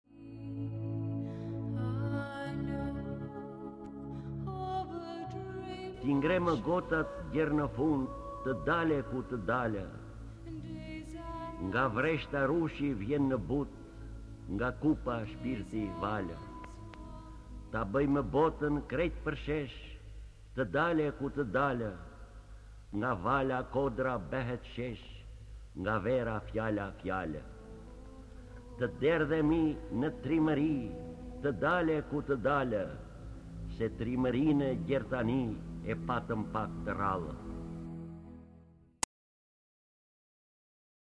D. AGOLLI - T'I NGREMË GOTAT Lexuar nga D. Agolli KTHEHU...